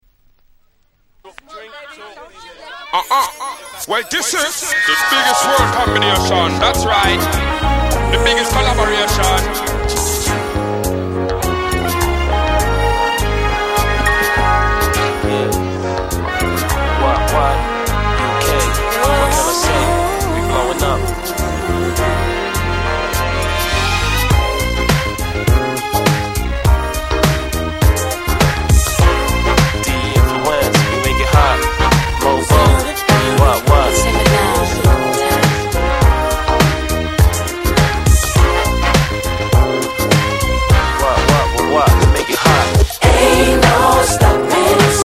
みんなで盛り上がれる鉄板曲なだけに、使い勝手もバッチリ保証された1枚。